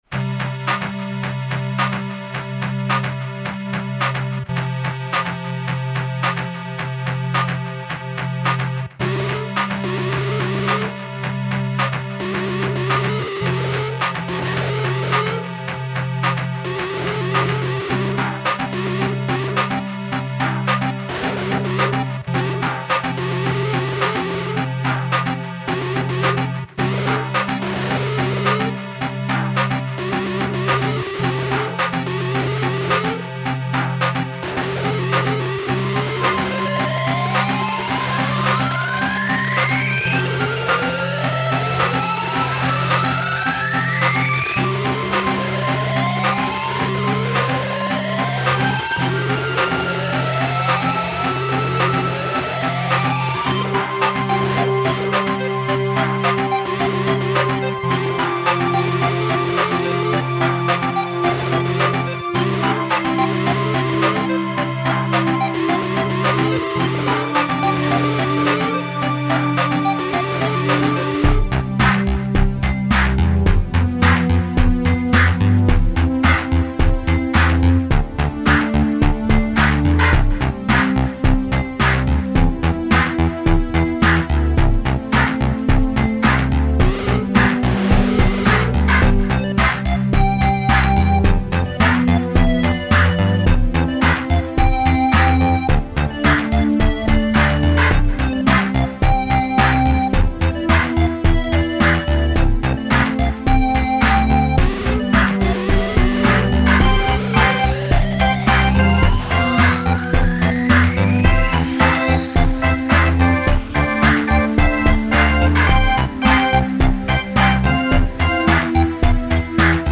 File under: Cheap Electronica